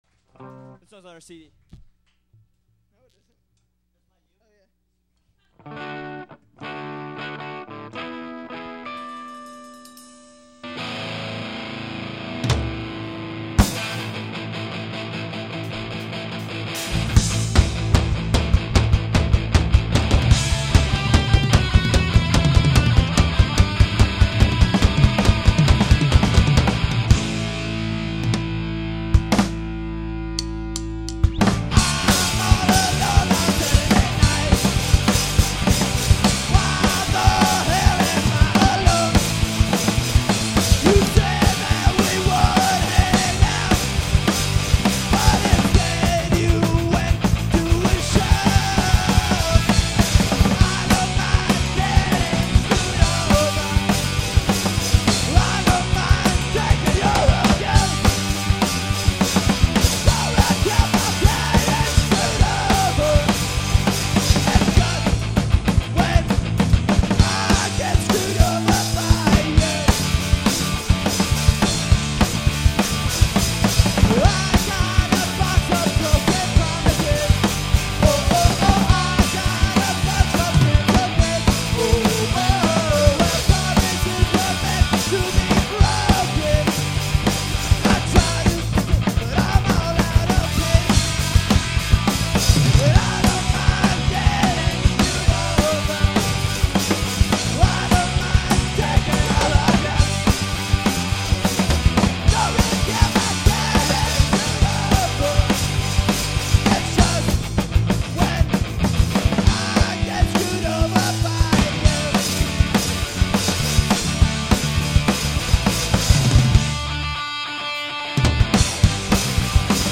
a live song recorded